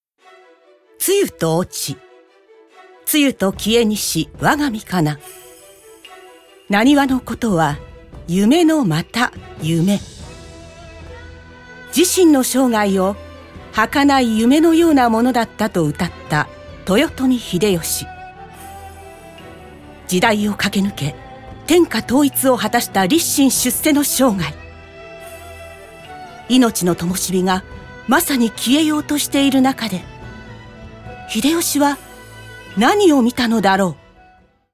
ボイスサンプル
1.新録 歴史NA(人物①)